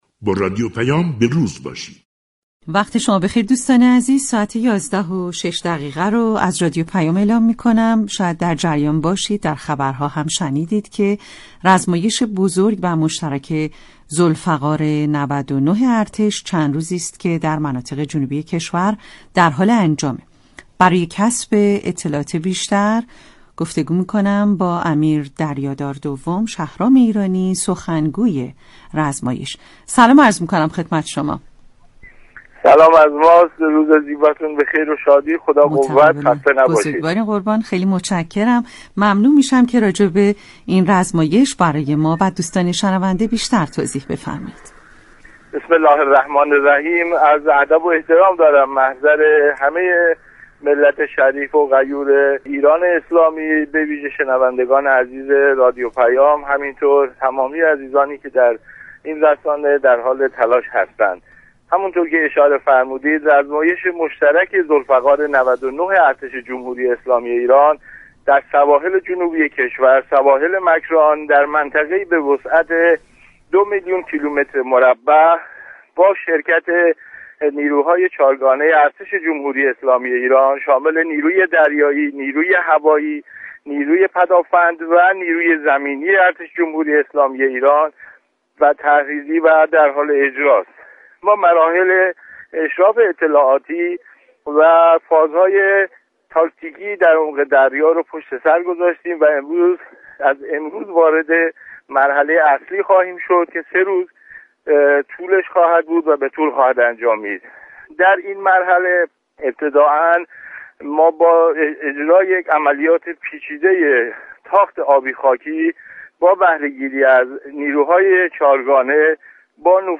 امیر دریادار دوم شهرام ایرانی، سخنگوی رزمایش مشترك ذوالفقار 99 ارتش در گفتگو با رادیو پیام ، جزئیاتی از برگزاری این رزمایش غرور آفرین را در سواحل جنوبی كشور بازگو كرد .